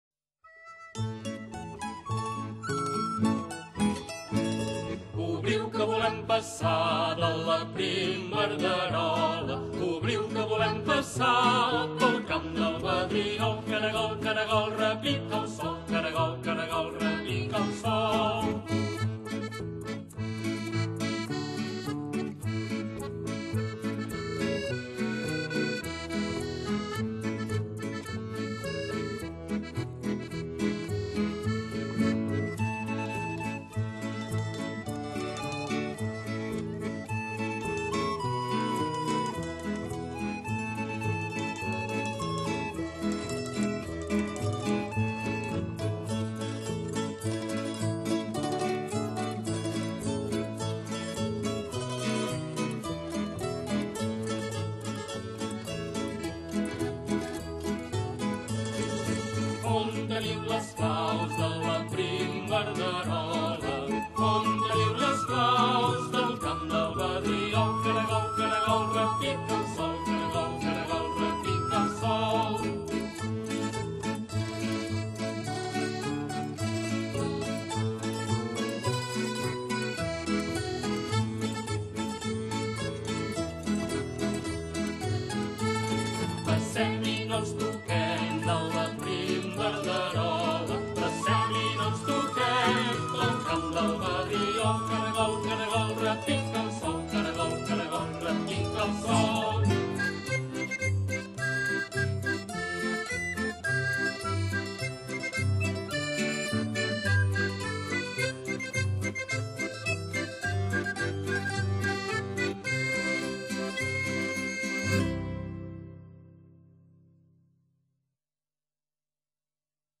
Tots Sants - Danses
Tradicional catalana